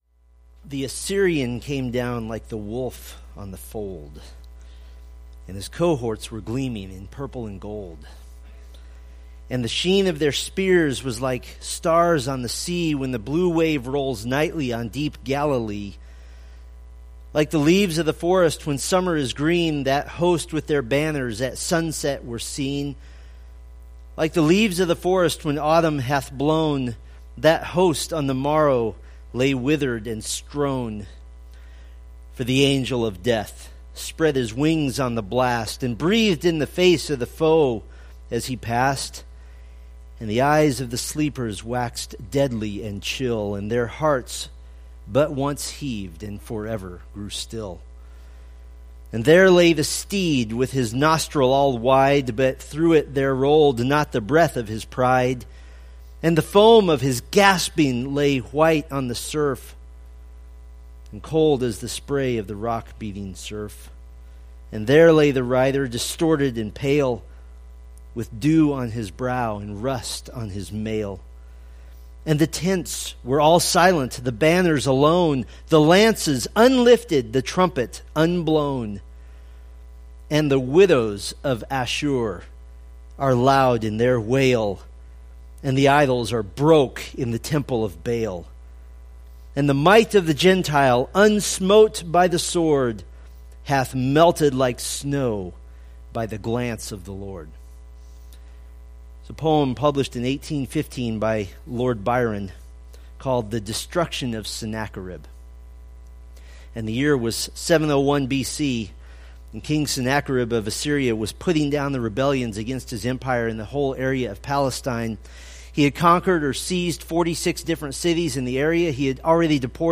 Preached October 23, 2016 from Isaiah 37:7-38